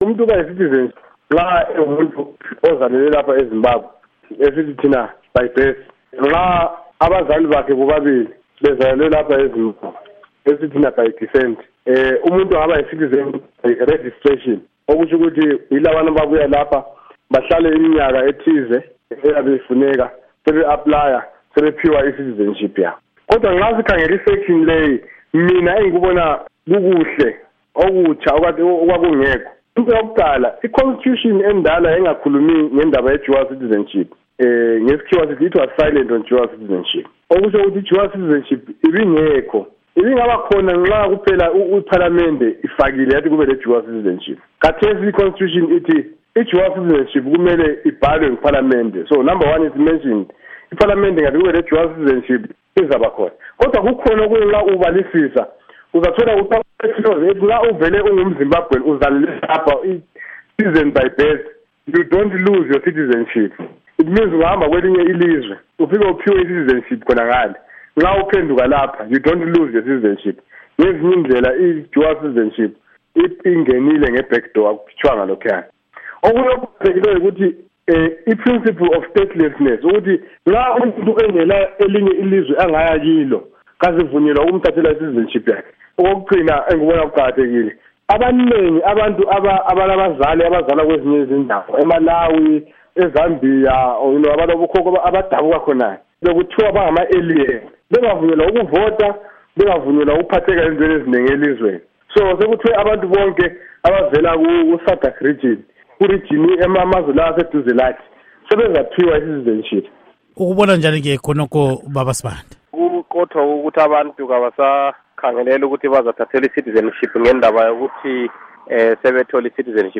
Ingxoxo Esiyenze Umnu.